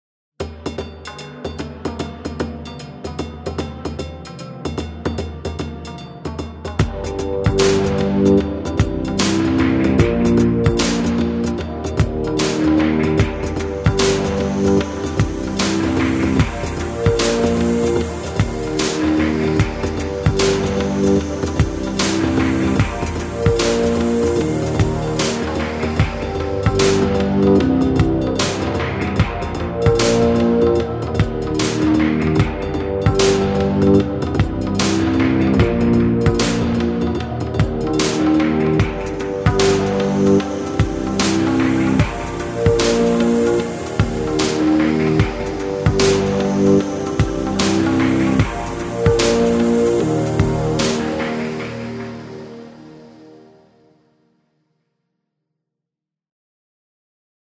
Pop groove for reality TV and sports.